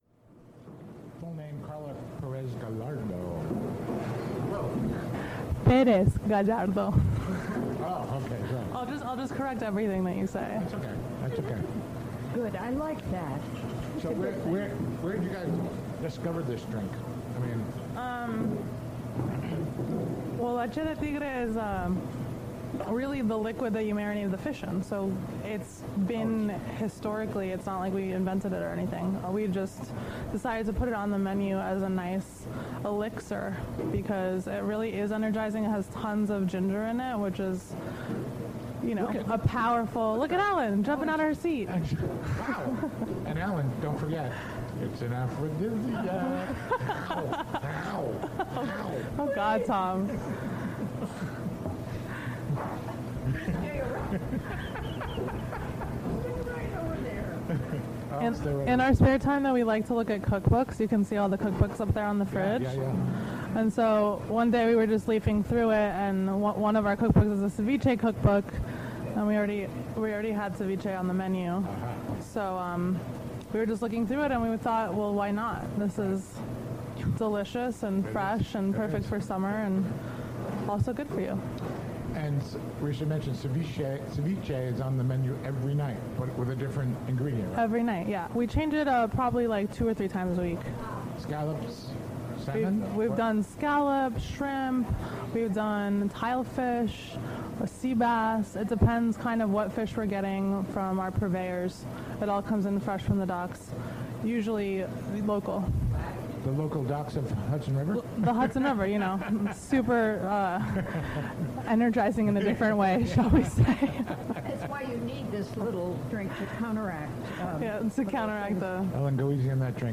Special Remote Broadcast: Jun 16, 2016: 4pm - 6pm